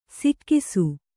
♪ sikkisu